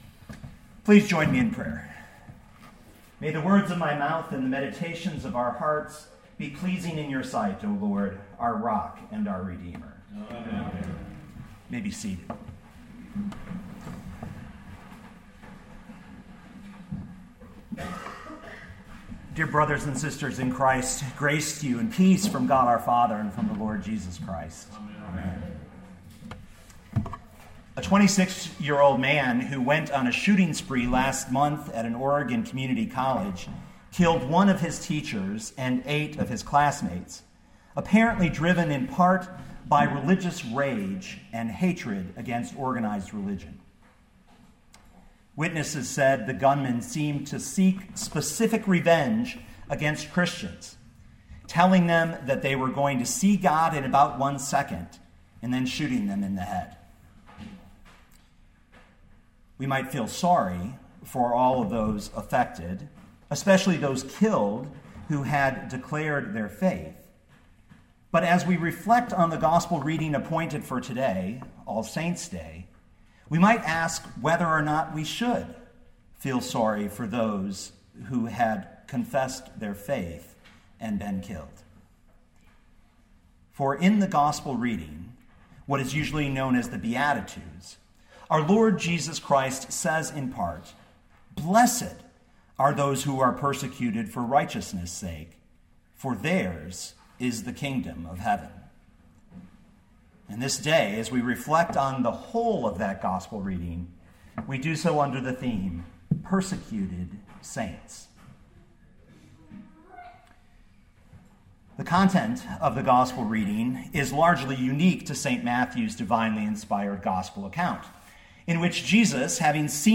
2015 Matthew 5:1-12 Listen to the sermon with the player below, or, download the audio.